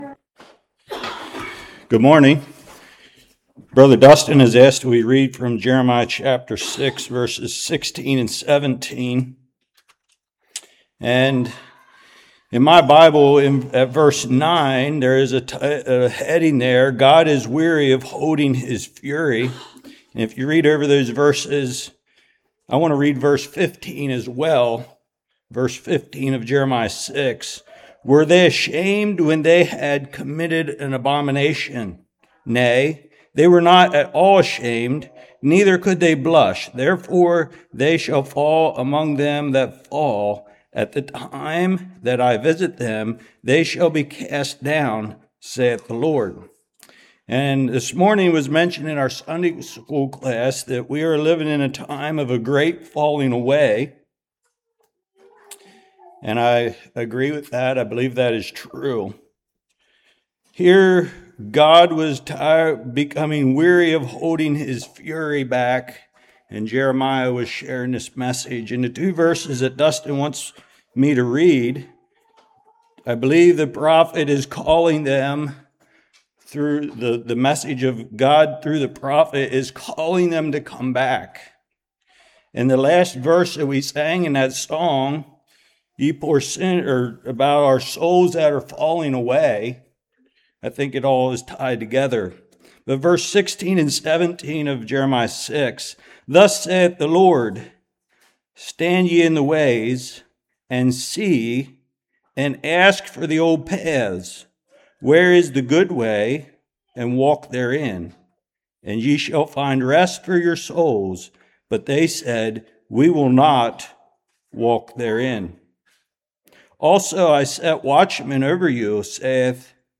Jeremiah 6:16-17 Service Type: Morning Looking Back at the History of the Church and What They Believe.